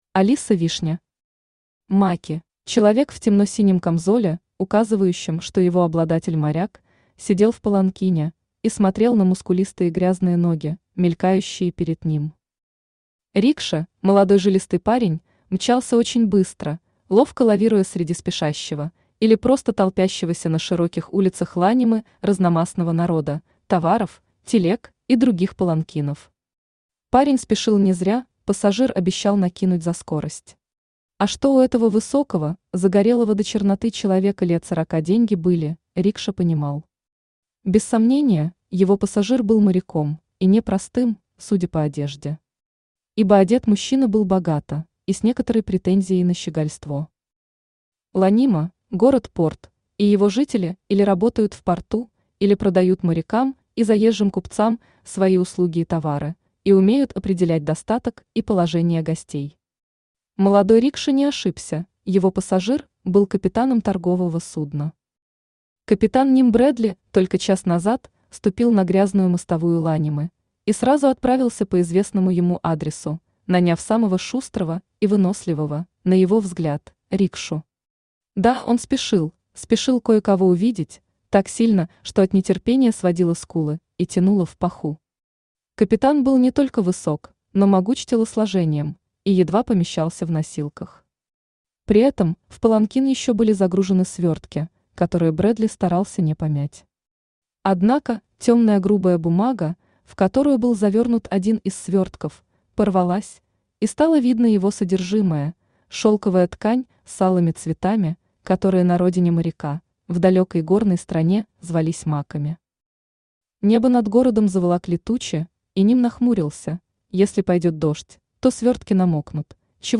Аудиокнига Маки | Библиотека аудиокниг
Aудиокнига Маки Автор Алиса Вишня Читает аудиокнигу Авточтец ЛитРес.